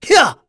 Lusicas-Vox_Attack2_kr.wav